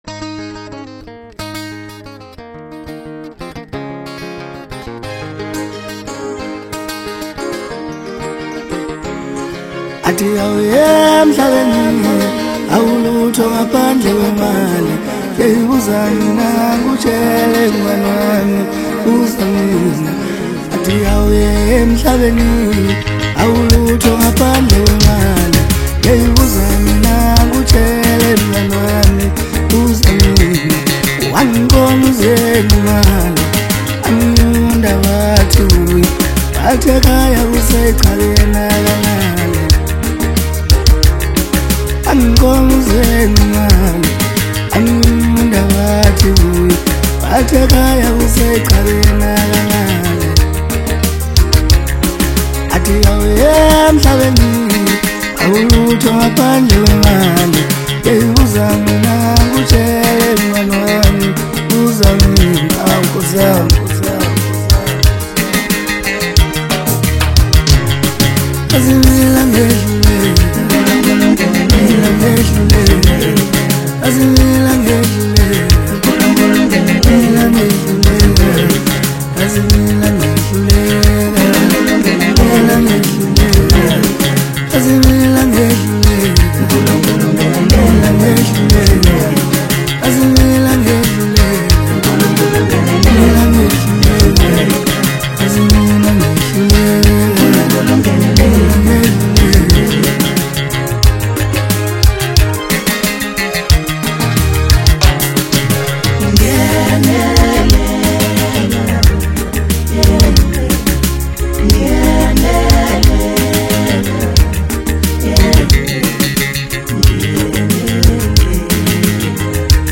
Home » Maskandi » Maskandi Music
Maskandi Songs